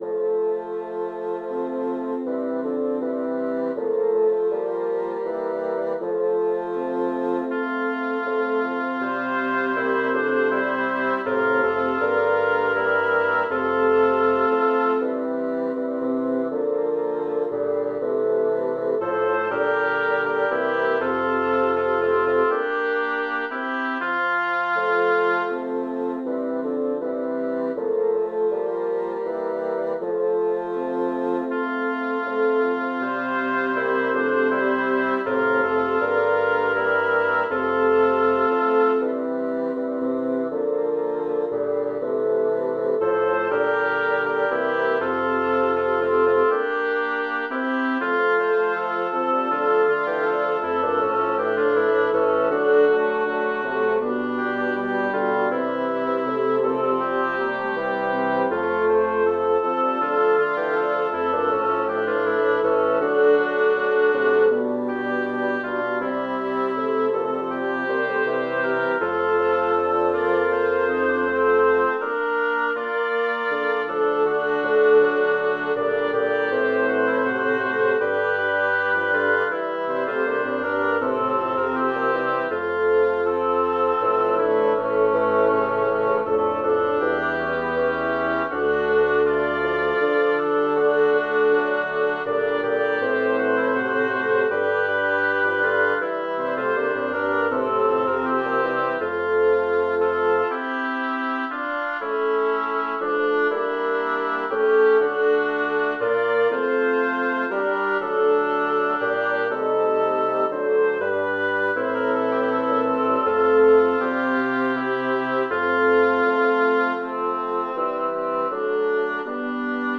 Title: O Iesu Christe, o Archiepiscope Composer: Giovanni Gabrieli Lyricist: Number of voices: 6vv Voicing: SATTBB Genre: Sacred, Motet
Language: Latin Instruments: A cappella